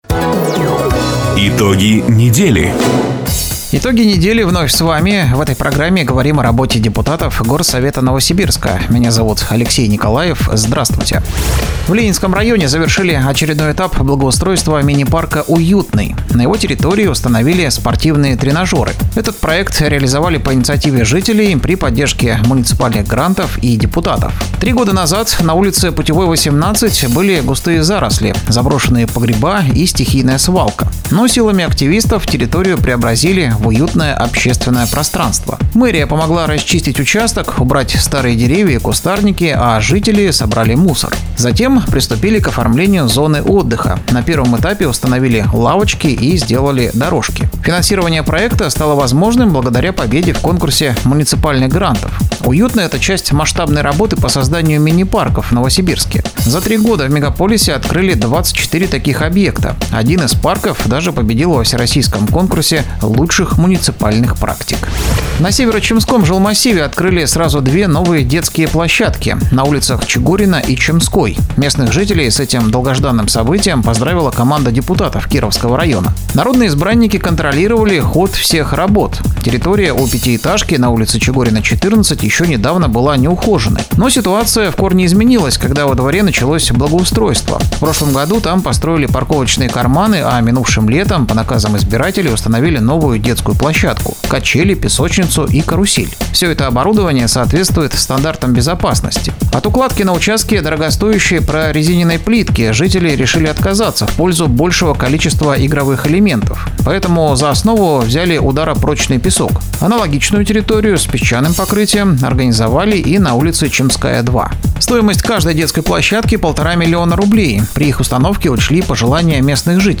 Запись программы "Итоги недели", транслированной радио "Дача" 06 сентября 2025 года